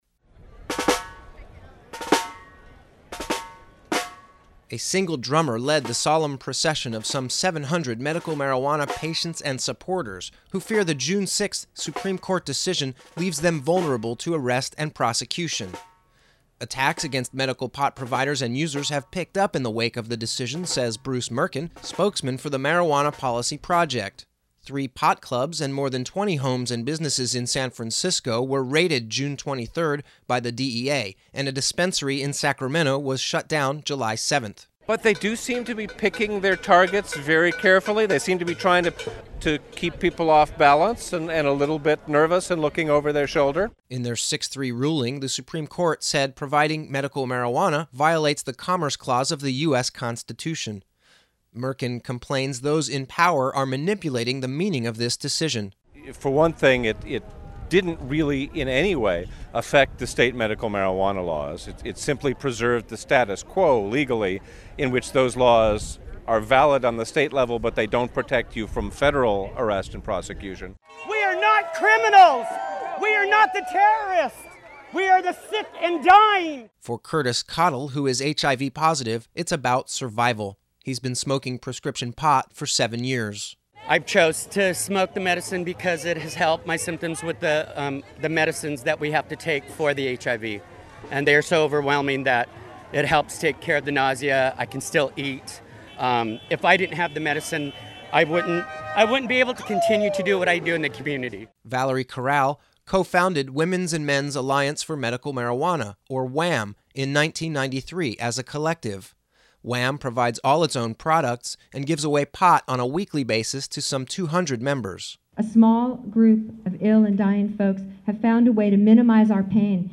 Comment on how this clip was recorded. Please read the LEDE on the air. 3:23